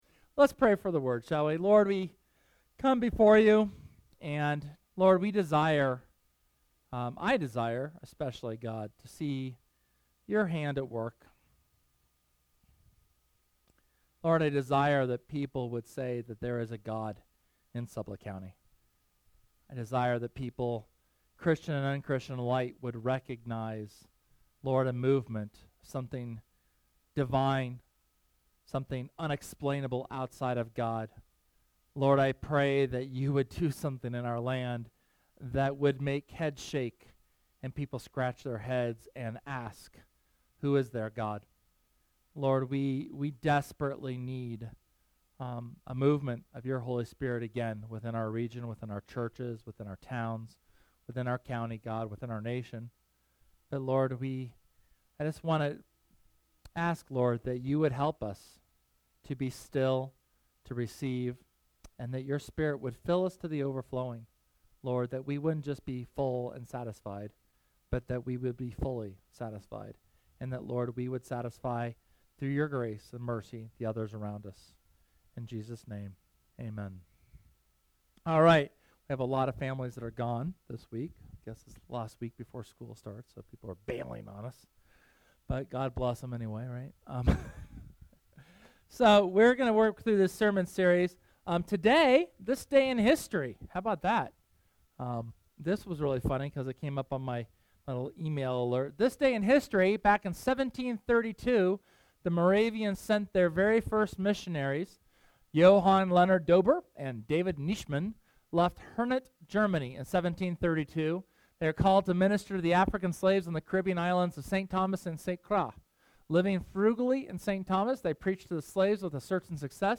SERMON: Revival (5)
The fifth sermon in our series, looking more closely at the characteristics of revival.